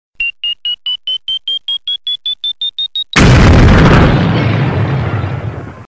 nada-dering-bom-waktu-367220.mp3